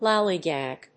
音節lal・ly・gag 発音記号・読み方
/lάːlig`æg(米国英語)/